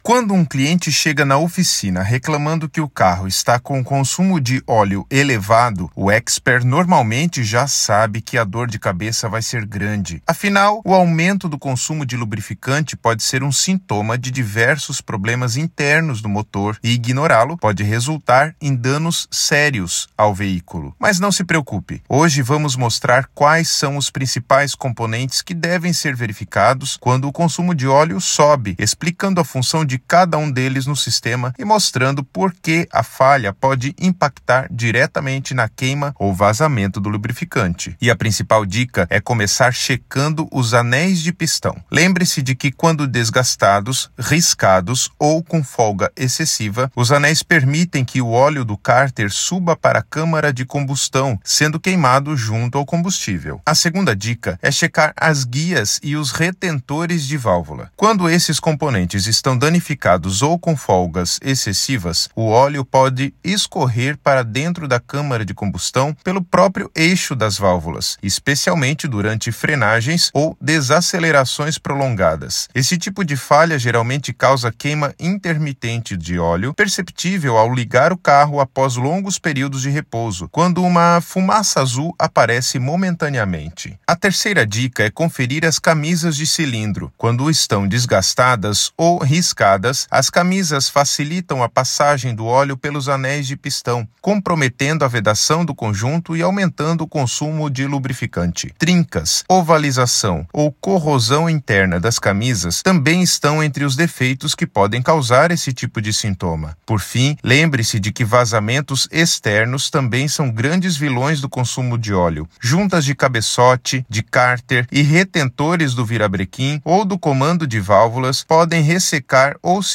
Narracao-01-consumo-de-oleo-elevado.mp3